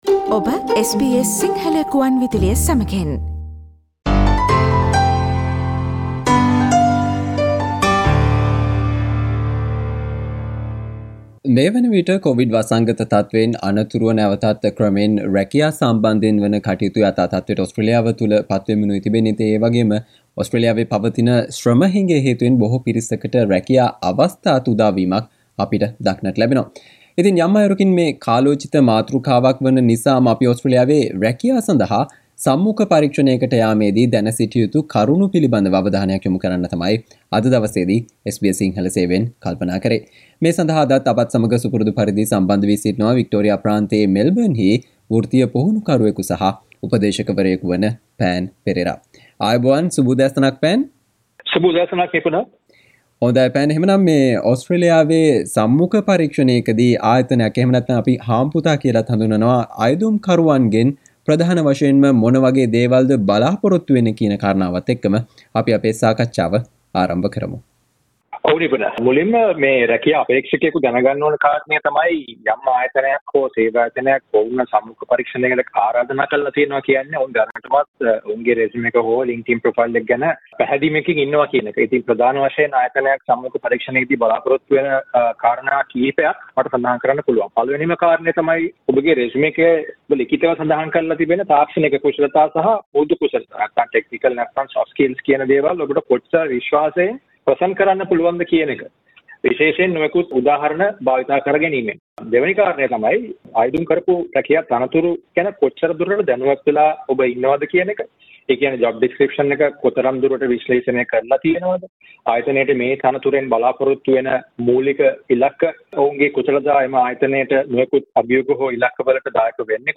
ඕස්ට්‍ර්ලියාවේ රැකියා සඳහා සම්මුඛ පරීක්ෂණයකට යාමේදී සහ මුහුණ දීමේදී දැන සිටිය යුතු කරුණු පිළිබඳව SBS සිංහල සේවය සිදු කල සාකච්චාවට සවන්දෙන්න